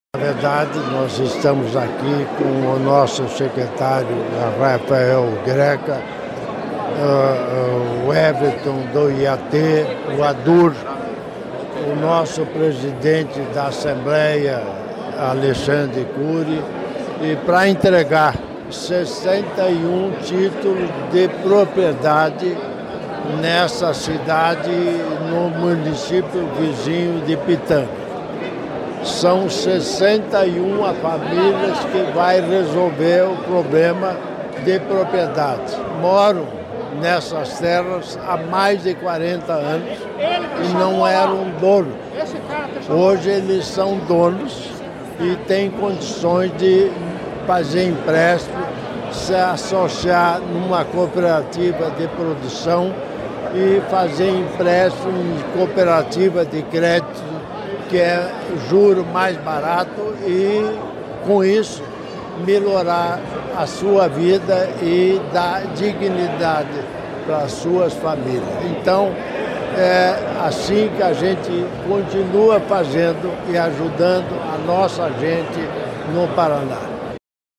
Sonora do governador em exercício Darci Piana sobre a regularização de 61 propriedades rurais em Boa Ventura de São Roque